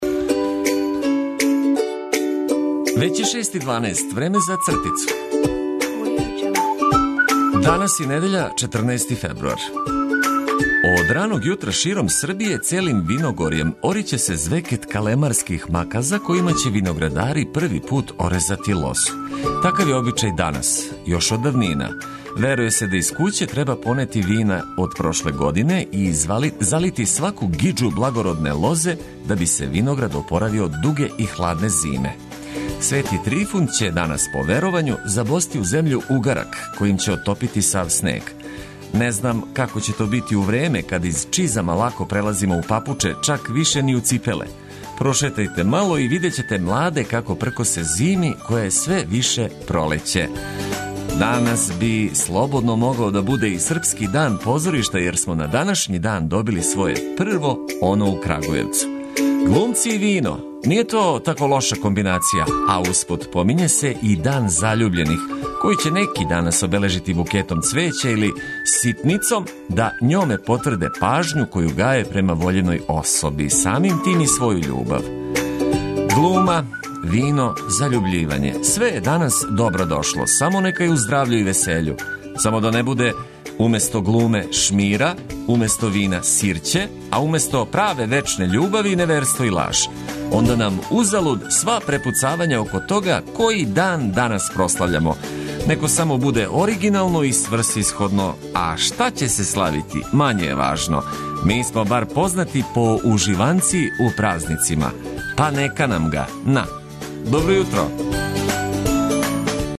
Недељу почињемо тако што пре устајања доручкујемо љубав, добру музику, и по коју важну вест.